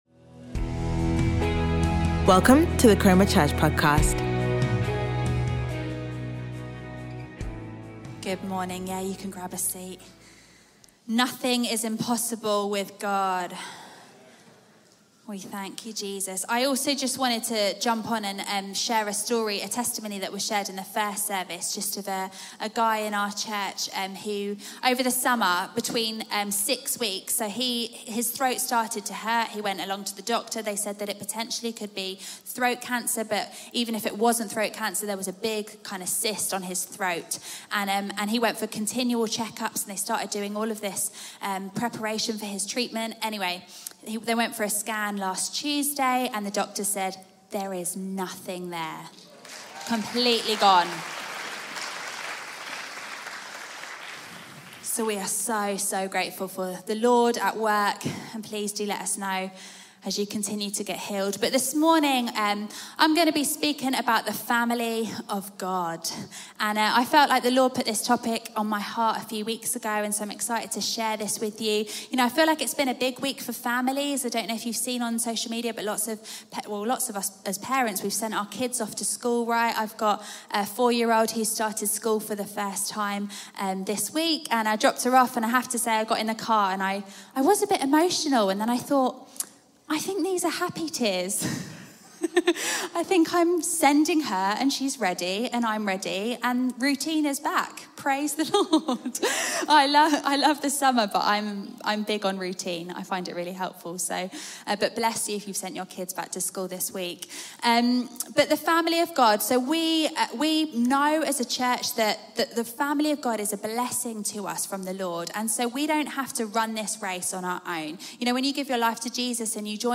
Chroma Church - Sunday Sermon